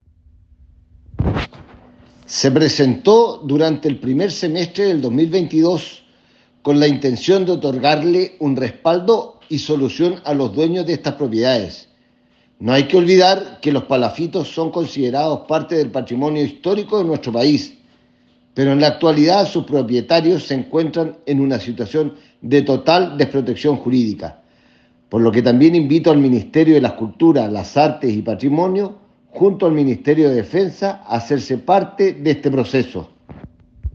Bórquez al respecto comentó: